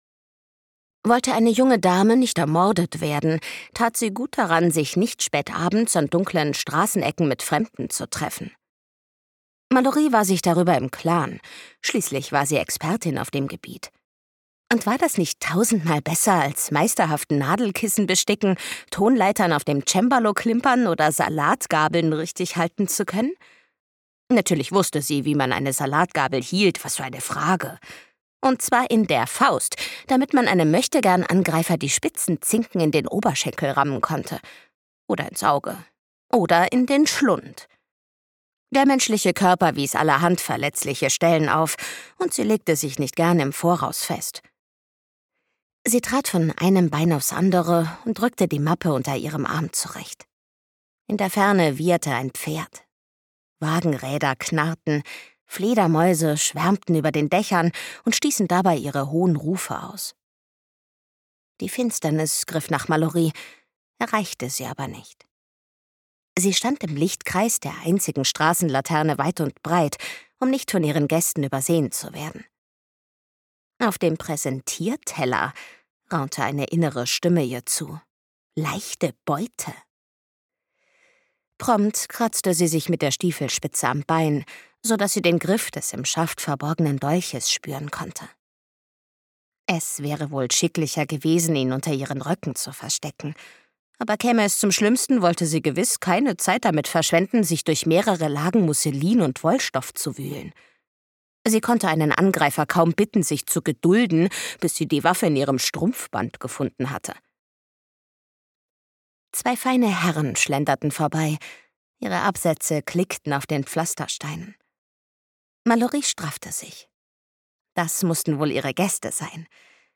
Die düstere und witzige Geister-Krimi-Romantasy von Bestsellerautorin Marissa Meyer
Mit Charme und Humor lässt sie die sympathischen Fontaine-Schwestern auf Geisterjagd gehen und den gruseligen Kriminalfall lösen.